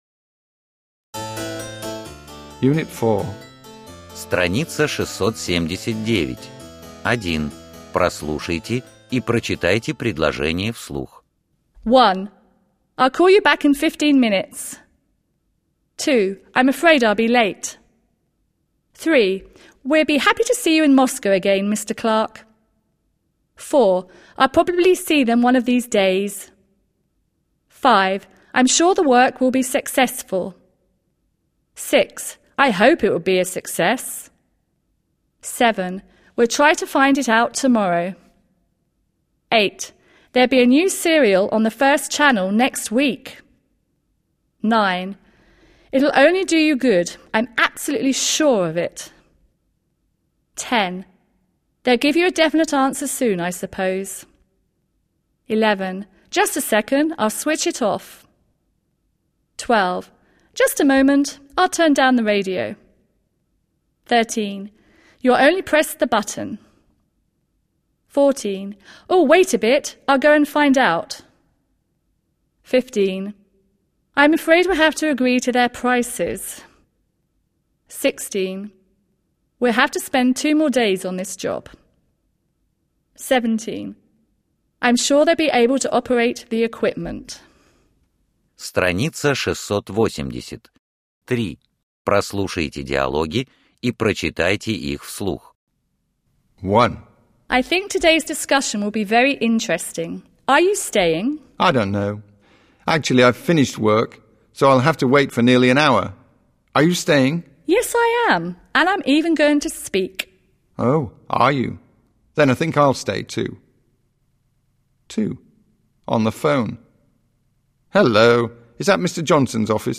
Начитанный носителями языка диск поможет развить навыки аудирования и поработать с произношением.